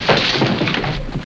Plaster Wall Break